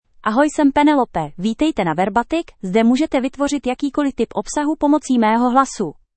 PenelopeFemale Czech AI voice
Penelope is a female AI voice for Czech (Czech Republic).
Voice sample
Female
Penelope delivers clear pronunciation with authentic Czech Republic Czech intonation, making your content sound professionally produced.